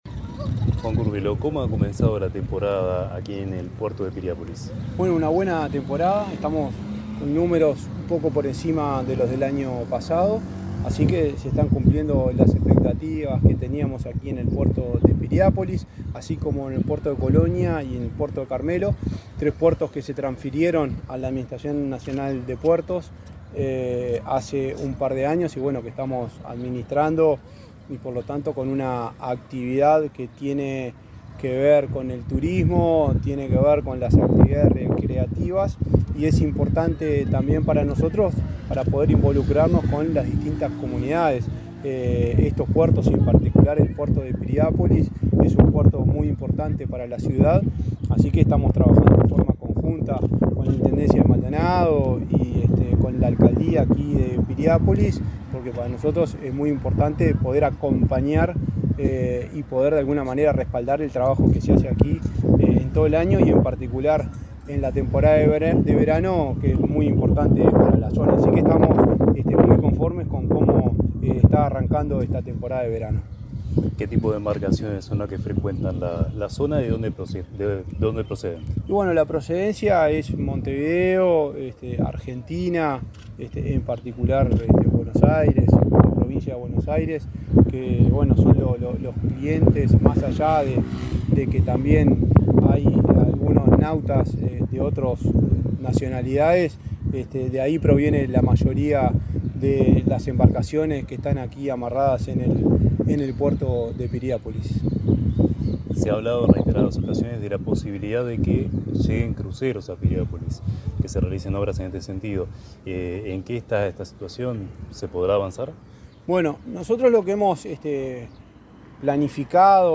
Entrevista al presidente de la ANP, Juan Curbelo
El presidente de la Administración Nacional de Puertos (ANP), Juan Curbelo, dialogó con Comunicación Presidencial en Piriápolis, departamento de